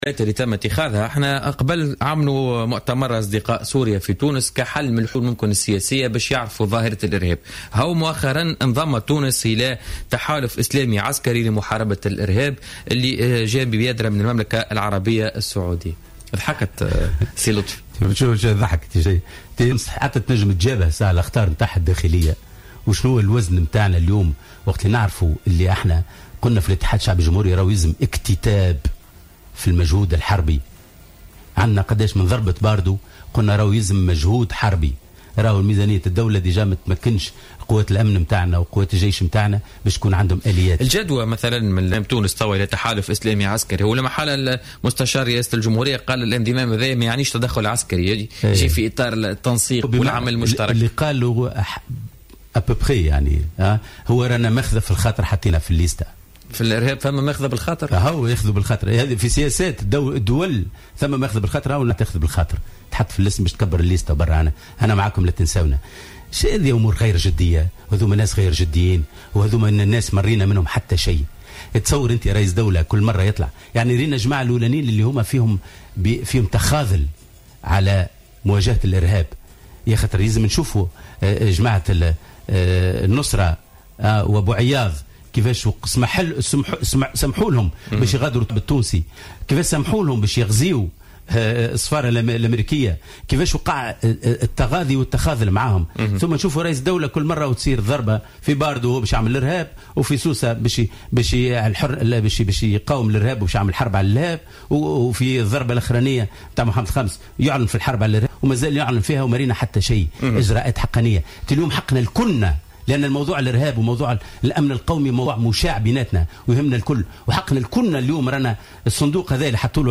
استغرب محمد لطفي المرايحي أمين عام حزب الاتحاد الشعبي الجمهوري، ضيف بوليتيكا اليوم الخميس 17 ديسمبر 2015 ، من انضمام تونس إلى ما يعرف بالتحالف الإسلامي لمحاربة تنظيم داعش، معتبرا أن وزن تونس عسكريا لا يسمح لها بذلك.